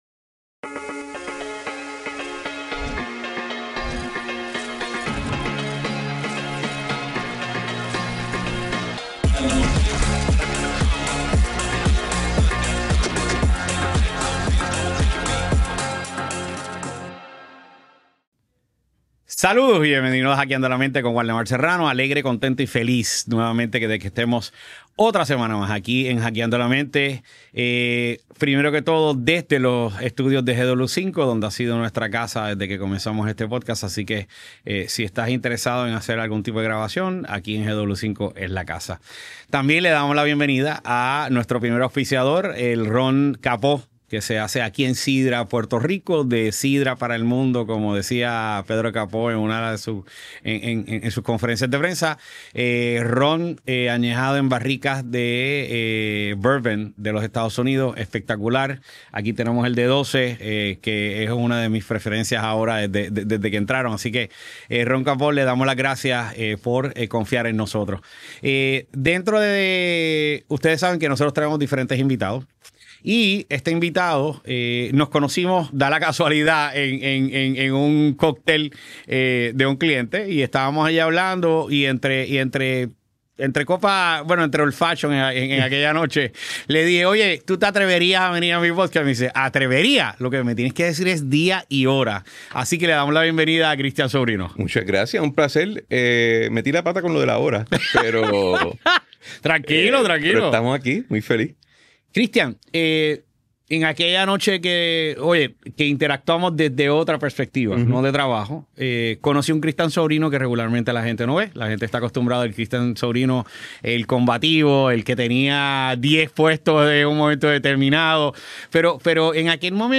En esta entrevista abre su corazón y comparte no solo las lecciones aprendidas en la esfera política, sino también cómo ha logrado reinventarse profesionalmente tras esos años de intensas experiencias.